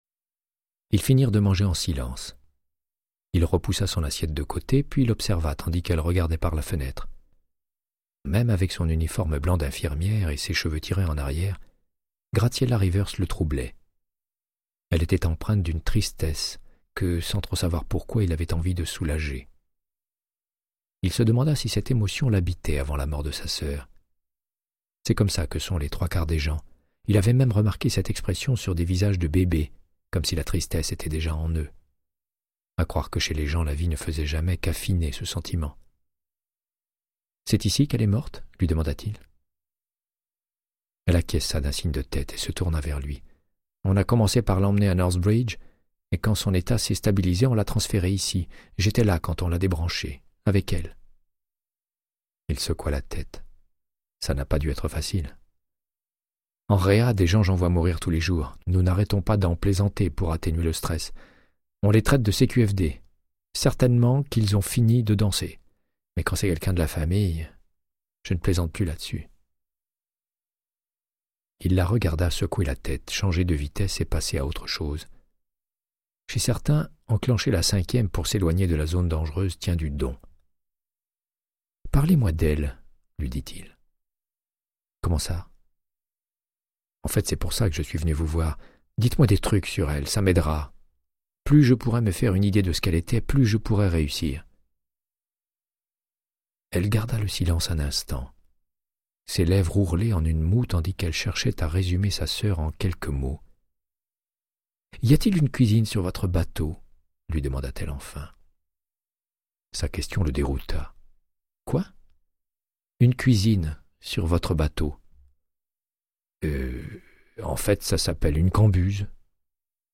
Audiobook = Créance de sang, de Michael Connellly - 26